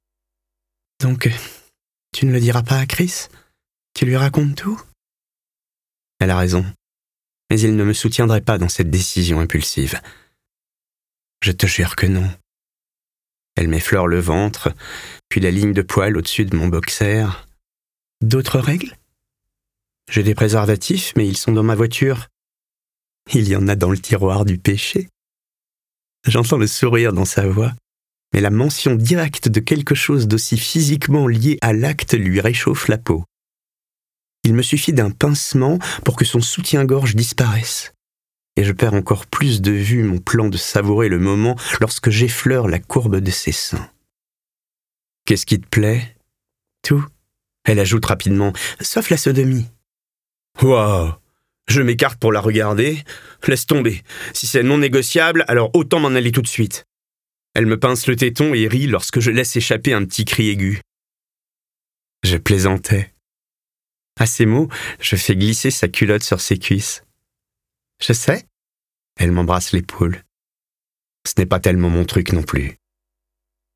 LIVRE AUDIO - My favorite Half-Night Stand (Christina Lauren)
32 - 64 ans - Baryton